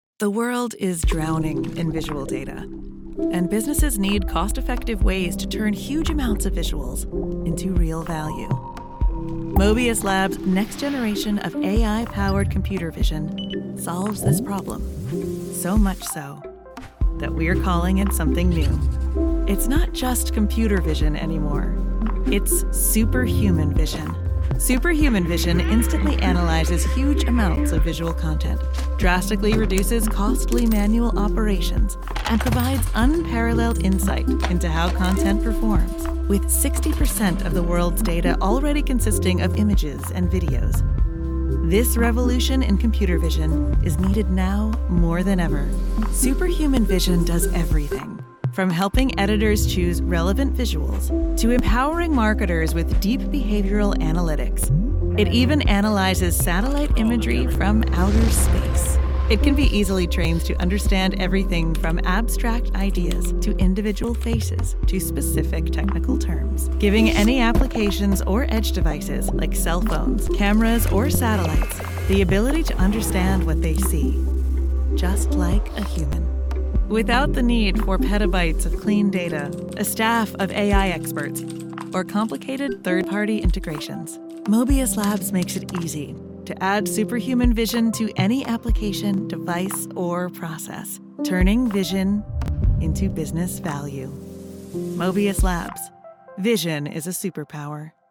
Natürlich, Zugänglich, Erwachsene, Freundlich, Warm
Erklärvideo
▸ Her voice is natural, real, and emotionally present.